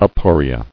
[a·po·ri·a]